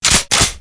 RELOAD.mp3